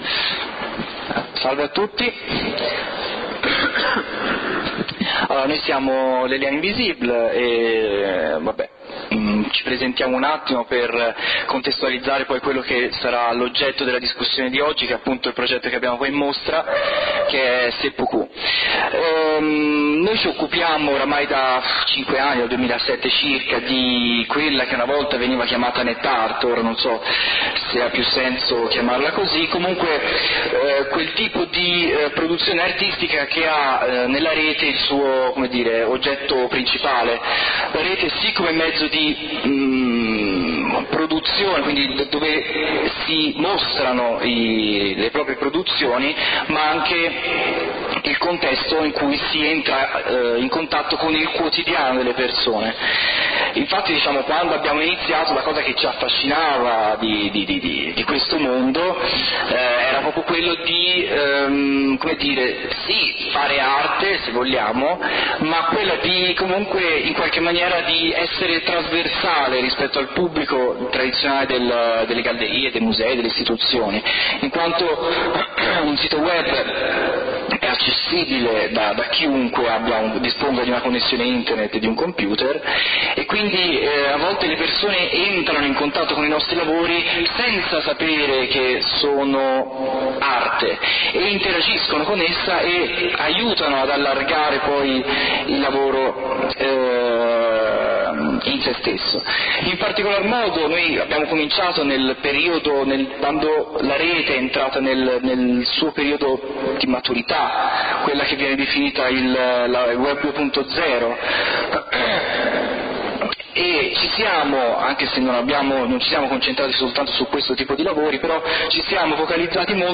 Incontro con il duo di artisti italiani creatori del progetto Seppukoo, applicazioni per suicidi rituali del proprio profilo Facebook.
ARTIST TALK / Les Liens Invisibles